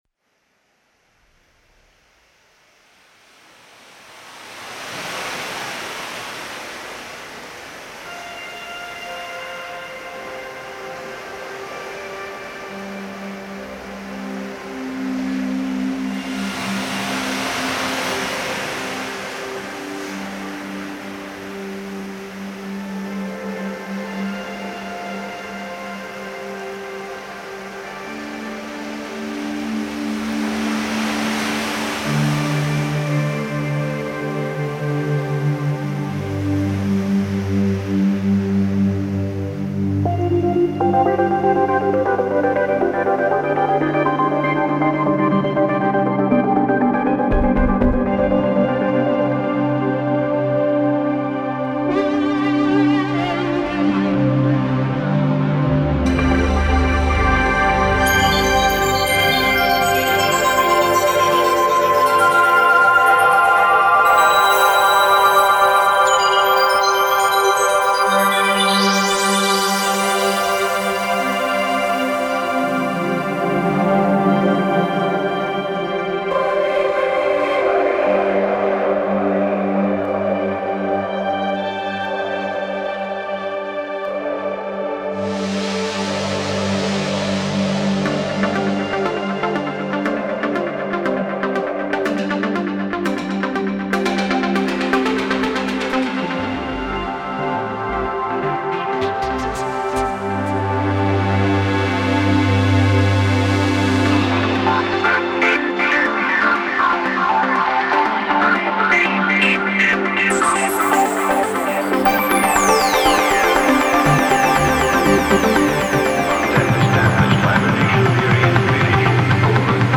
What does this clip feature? Genre: Psybient.